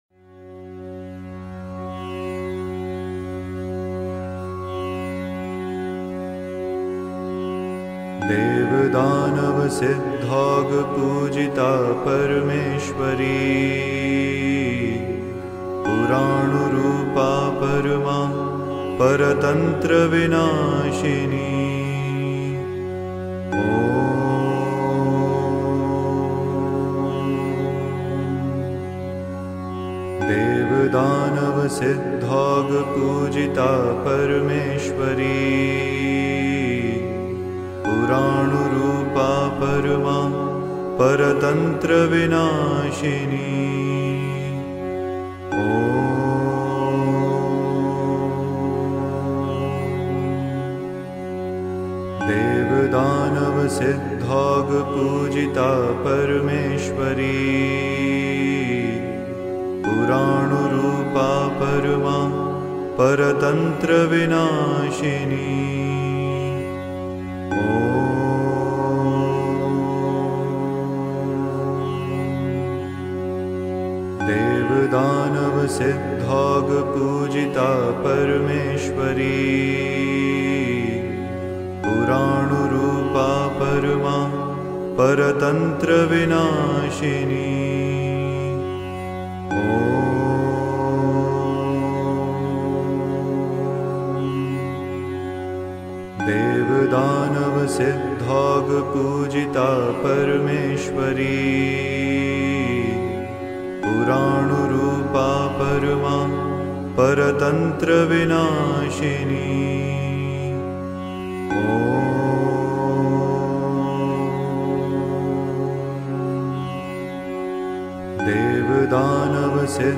🔊 تلفظ: DEV DANAV SIDDHAUGH PUJITA PARMESHWARI PURANU RUPA PARMA, PARTANTRA VINASHINI OM
4. اگر مایل بودی، هم‌زمان با موسیقی مانترا را با صدای آهسته تکرار کن.